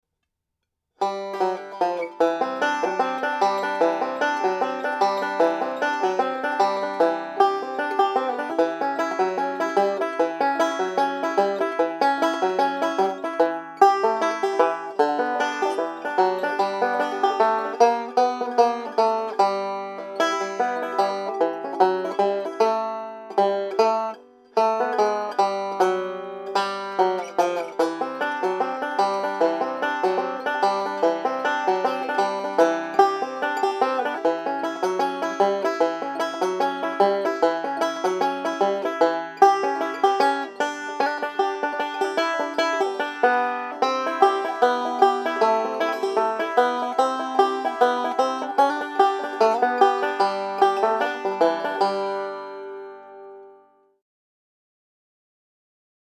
DIGITAL SHEET MUSIC - 5-STRING BANJO SOLO
Three-finger "Scruggs style"
learning speed and performing speed